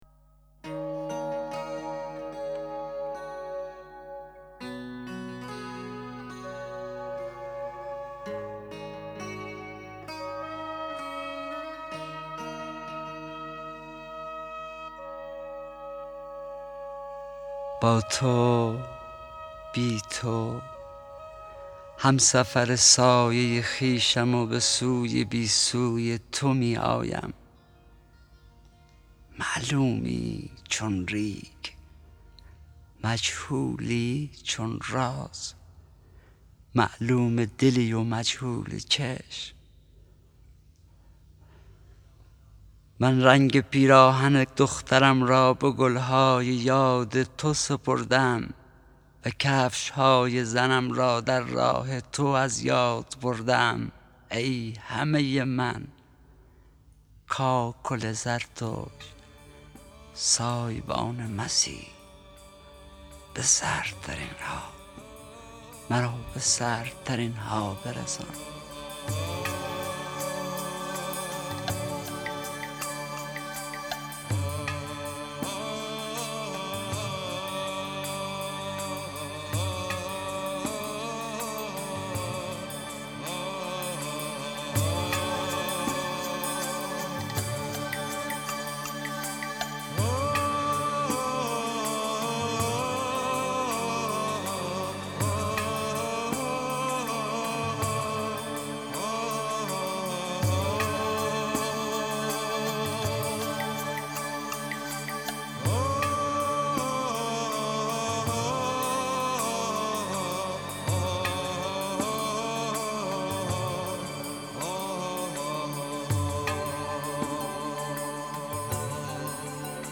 دانلود دکلمه بانو با صدای حسین پناهی با متن دکلمه
گوینده :   [حسین پناهی]